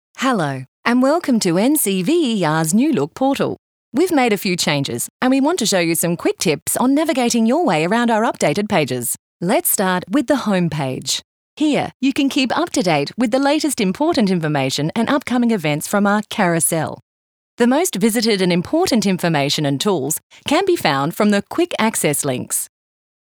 Inglés (Australia)
Comercial, Travieso, Versátil
E-learning